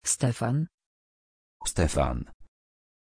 Aussprache von Stefan
pronunciation-stefan-pl.mp3